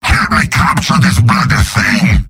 Giant Robot lines from MvM. This is an audio clip from the game Team Fortress 2 .
{{AudioTF2}} Category:Demoman Robot audio responses You cannot overwrite this file.